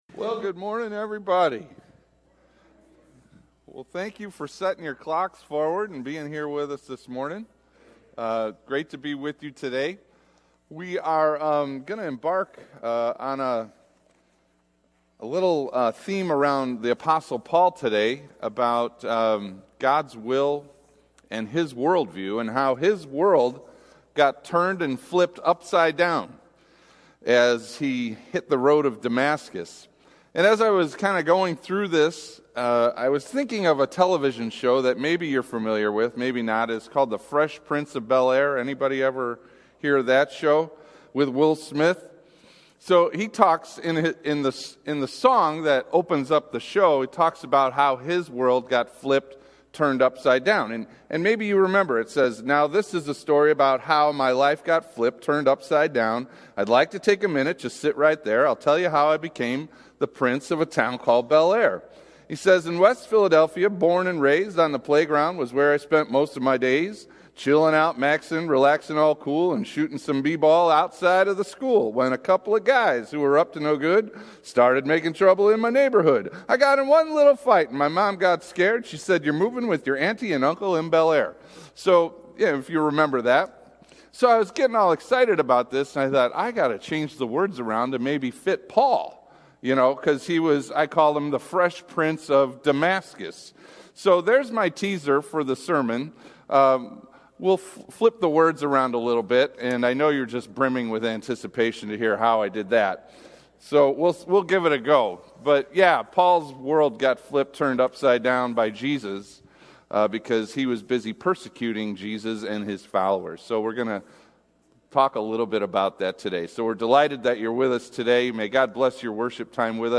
Mar 11 / Divine – The Fresh Prince of Damascus – Lutheran Worship audio